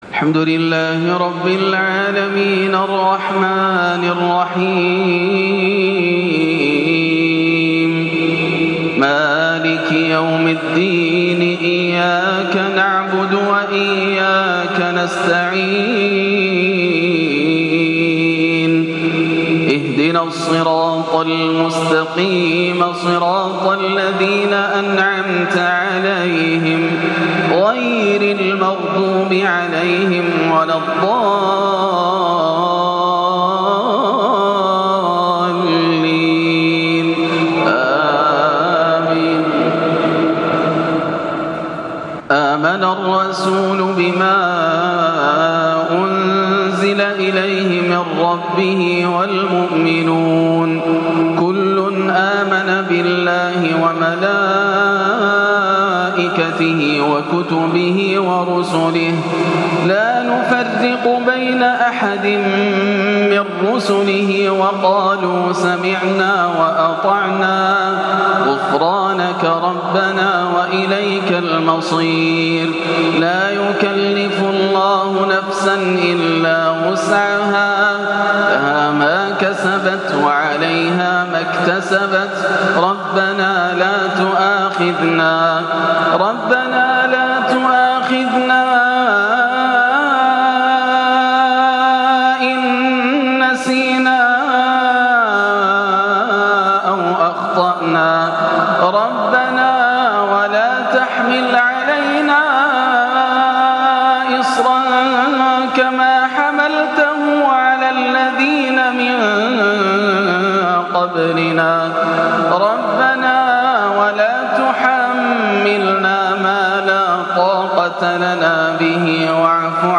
آيات الثبات على الإيمان - أواخر البقرة وماتيسر من آل عمران - صلاة الجمعة 28-6 > عام 1439 > الفروض - تلاوات ياسر الدوسري